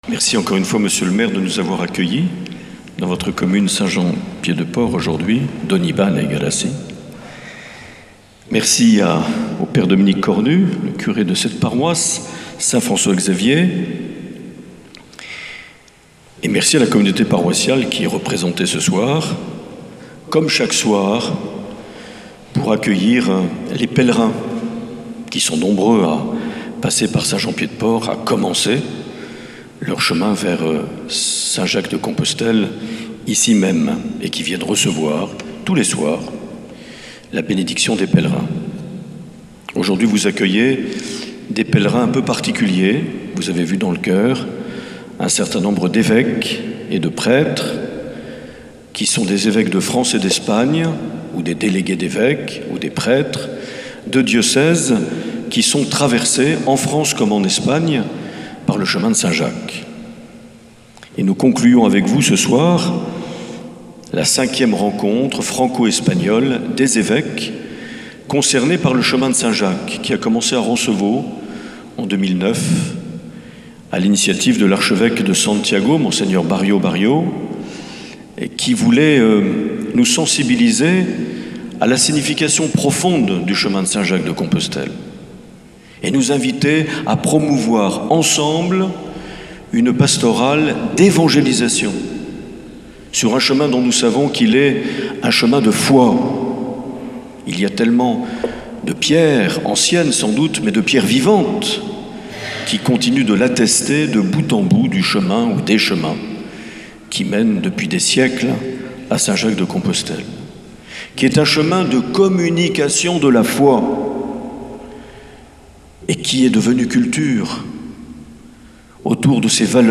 9 juillet 2015 - Eglise Notre Dame de Saint-Jean-Pied-de-Port - Messe de clôture de la 5ème rencontre franco-espagnole des évêques concernés par le Chemin de Saint Jacques de Compostelle.
Accueil \ Emissions \ Vie de l’Eglise \ Evêque \ Les Homélies \ 9 juillet 2015 - Eglise Notre Dame de Saint-Jean-Pied-de-Port - Messe de (...)
Une émission présentée par Monseigneur Marc Aillet